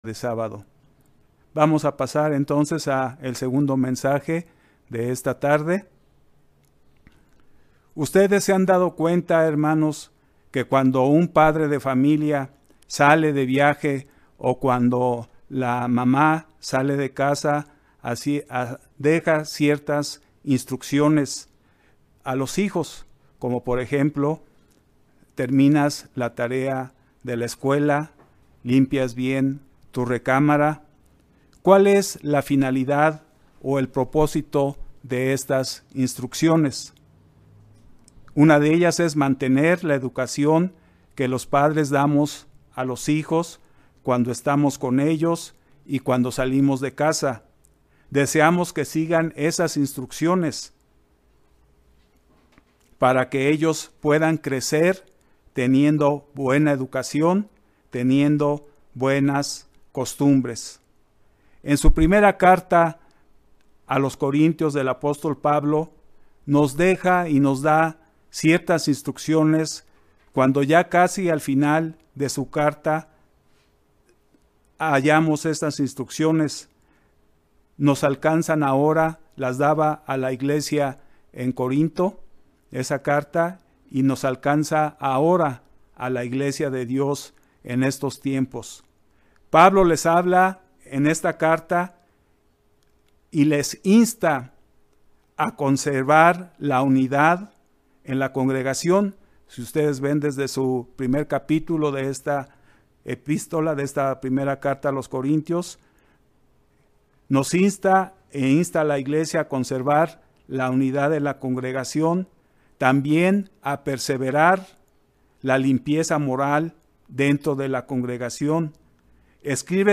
Una vez que Jesucristo volvió al Padre, nos dejó una serie de instrucciones claras a seguir mientras esperamos su retorno a la Tierra: Velar, Mantenernos firmes en la fe, esforzarnos y hacer todo con amor. Mensaje entregado el 6 de febrero de 2021.